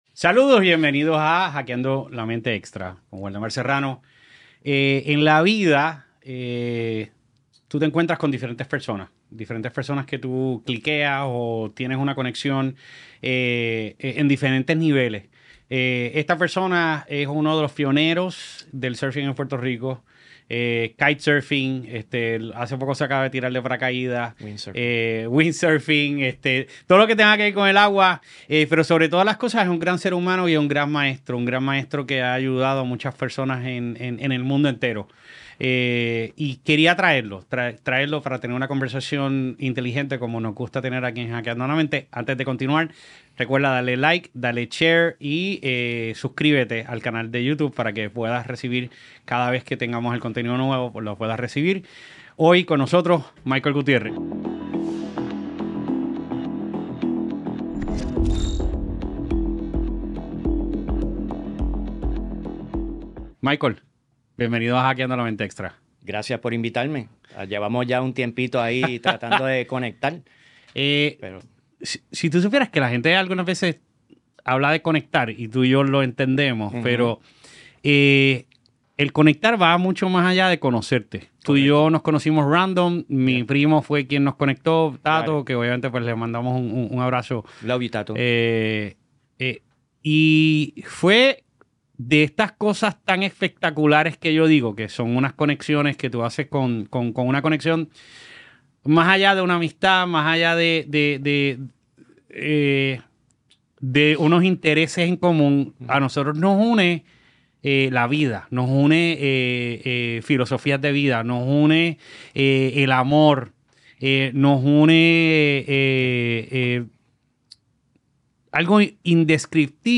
Acompáñanos en esta enriquecedora conversación, donde descubriremos cómo cultivar una mentalidad positiva y abrirnos a las oportunidades que el universo tiene para ofrecer.